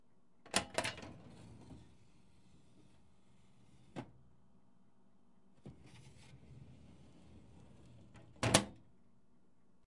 文件抽屉的打开和关闭
描述：金属文件抽屉的开启和关闭。